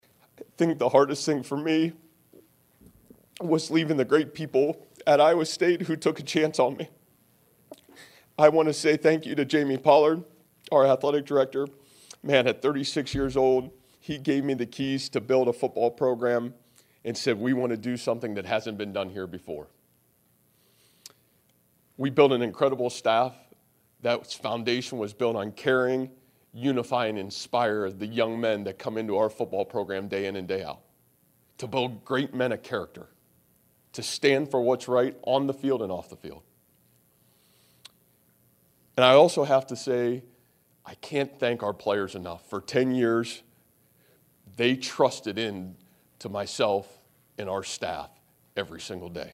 Campbell also got emotional when talking about what he leaves behind at Iowa St.